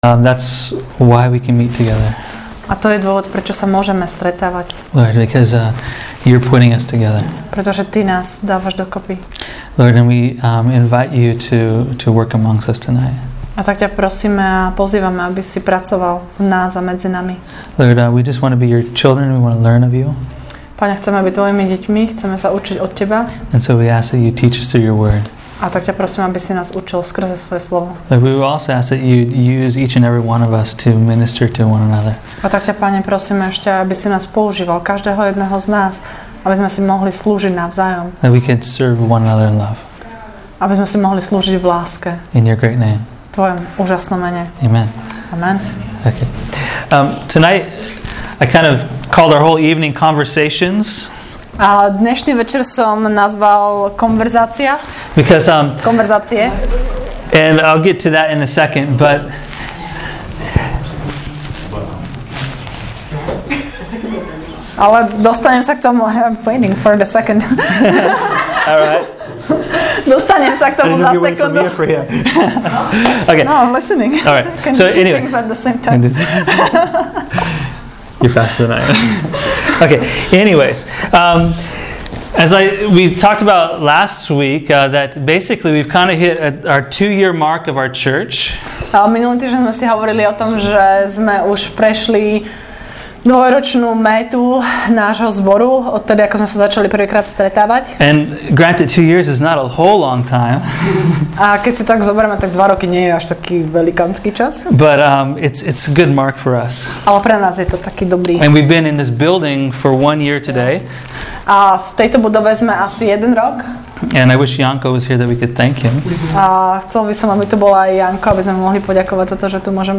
“Conversations” was a special service reflecting on our last two years as a church, sharing words of hope, and dreaming together of our future.